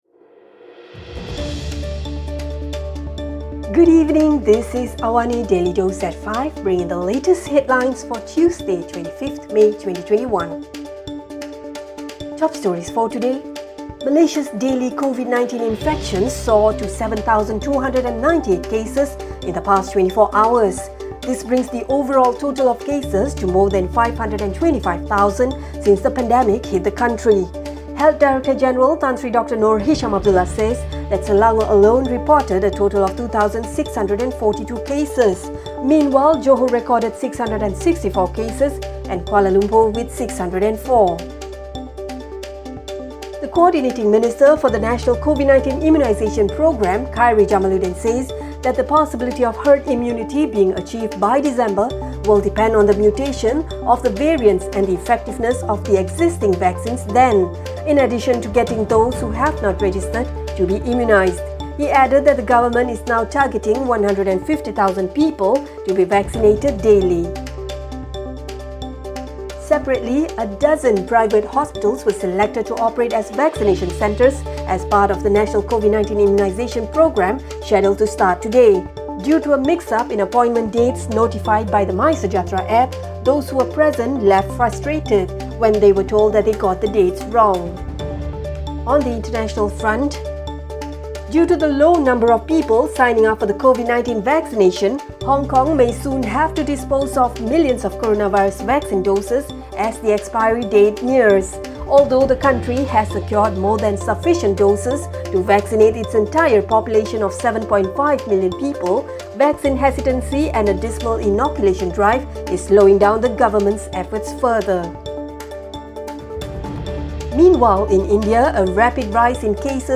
Listen to the top stories of the day, reporting from Astro AWANI newsroom — all in 3-minutes.